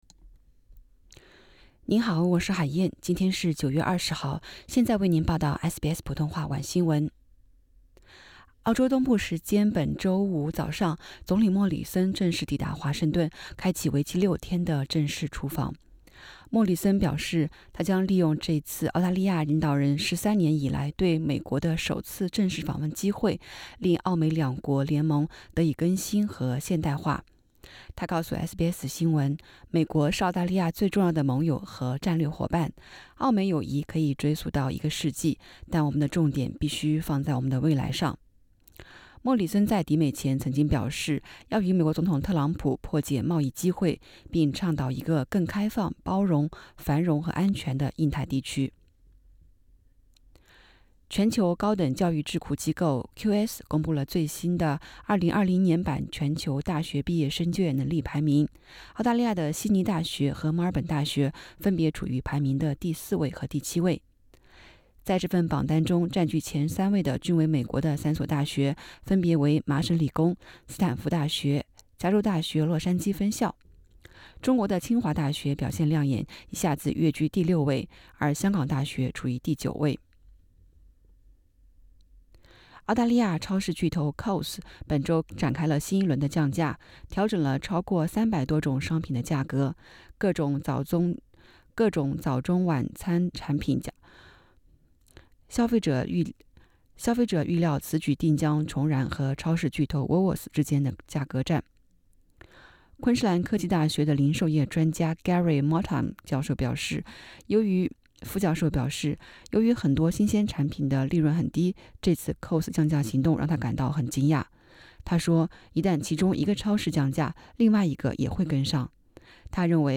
SBS晚新闻（9月20日）